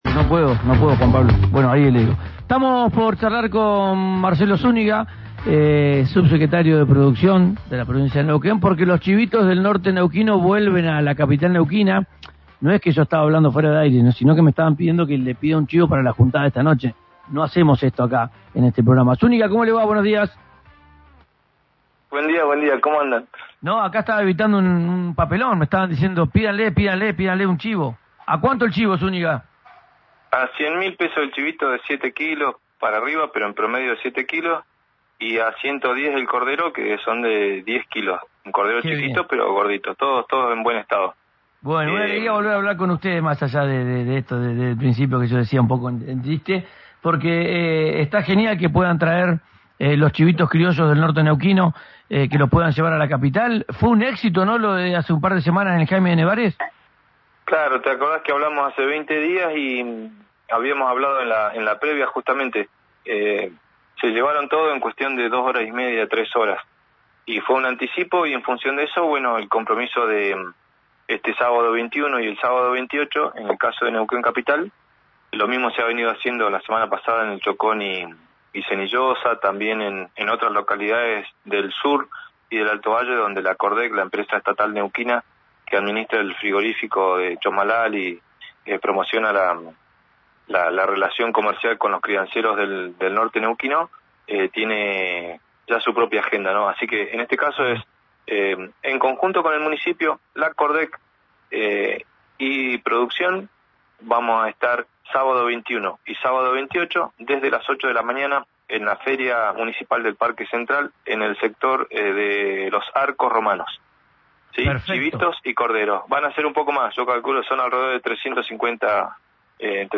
Escuchá a Marcelo Zúñiga, en RADIO RÍO NEGRO: